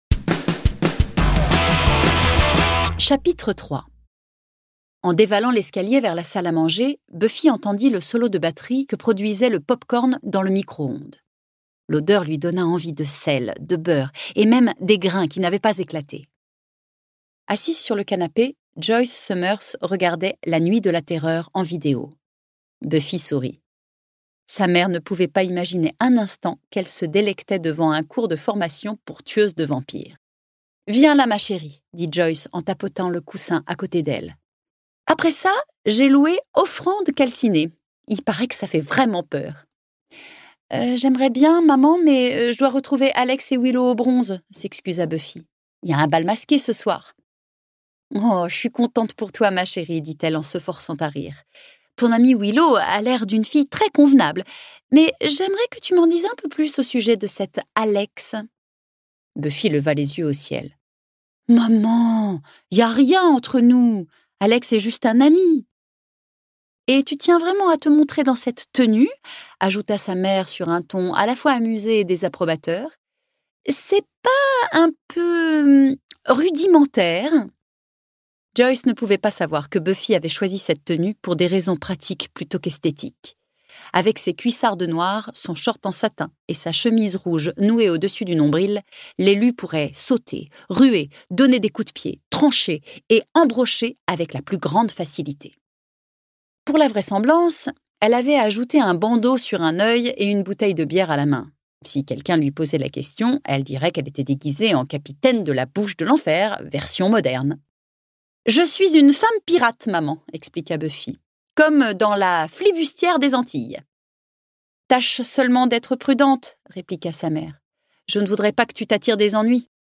» Retrouvez l'univers culte de la Tueuse mythique, ses alliés et ses ennemis de toujours !Ce livre audio est interprété par une voix humaine, dans le respect des engagements d'Hardigan.